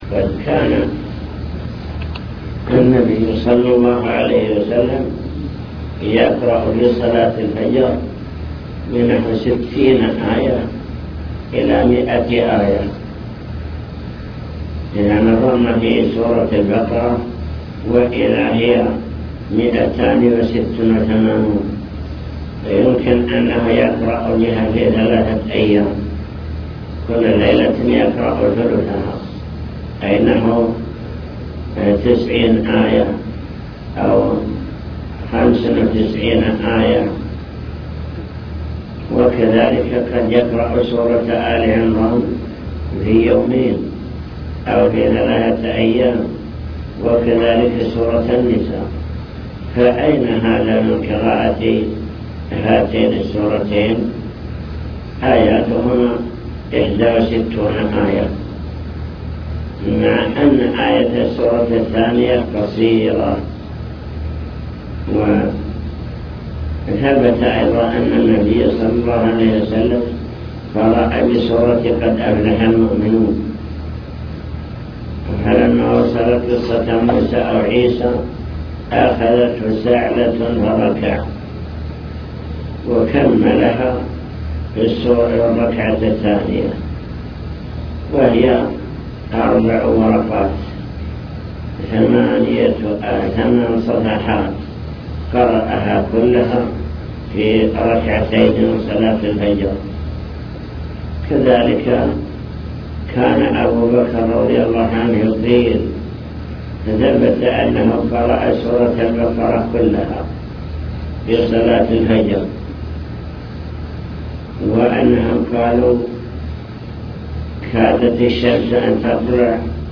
المكتبة الصوتية  تسجيلات - لقاءات  حول أركان الصلاة (لقاء مفتوح)